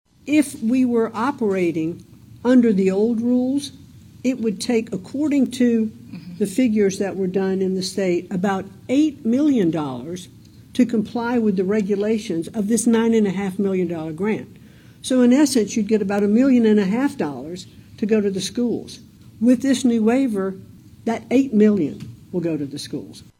U-S EDUCATION SECRETARY LINDA MCMAHON MADE THE ANNOUNCEMENT TODAY (WEDNESDAY) IN DENISON.
MCMAHON AND GOVERNOR REYNOLDS MADE THEIR COMMENTS AFTER TOURING AN ELEMENTARY SCHOOL IN DENISON AND VISITING WITH STUDENTS.